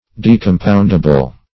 \De`com*pound"a*ble\